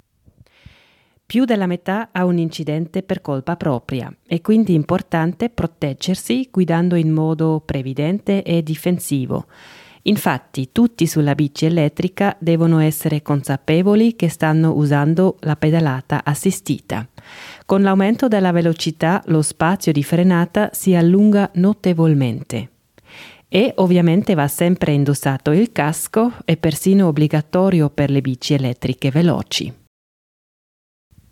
L’UPI offre un servizio nuovo che permette di scaricare i file audio di alcune citazioni del comunicato stampa.